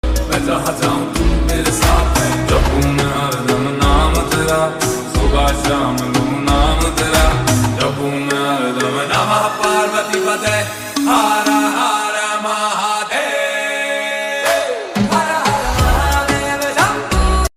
Enjoy calm, devotional Mahadev vibes with echo effect.